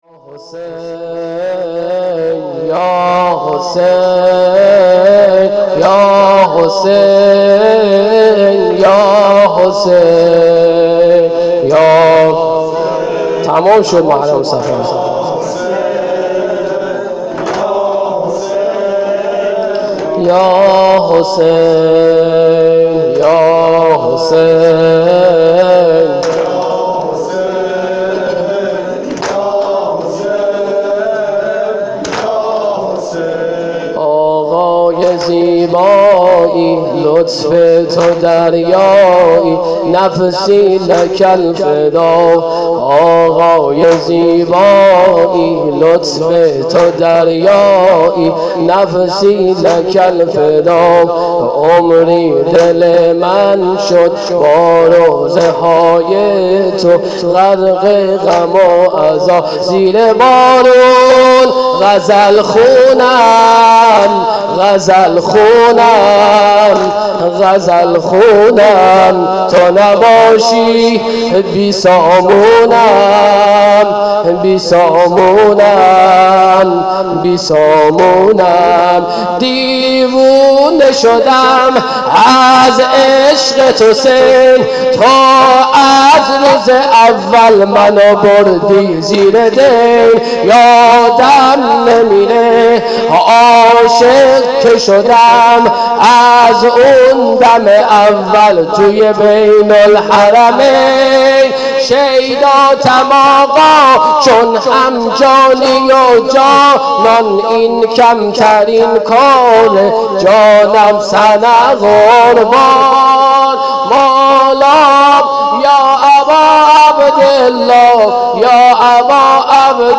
هیأت زوارالزهرا سلام اللّه علیها